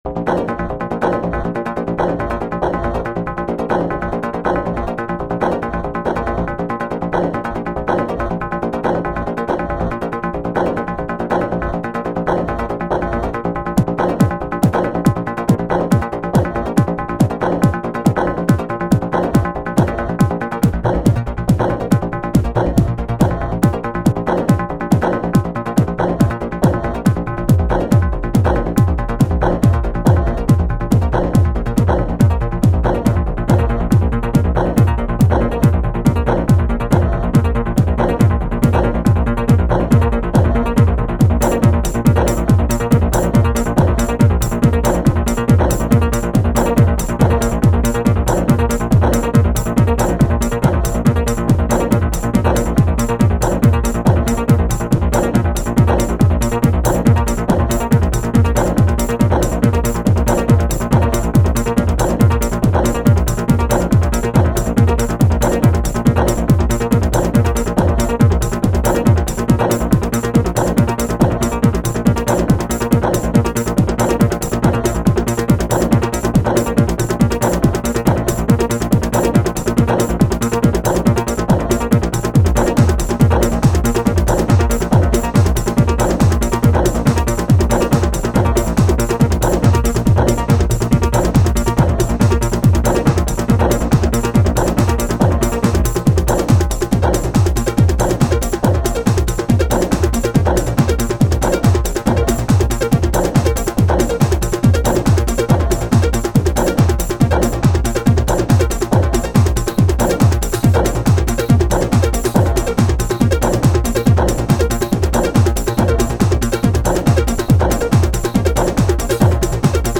:play Korg Wavestation SR Pure - Drummer I:
korg_wavestation_sr_-_sounds_demo_-_drummer_01.mp3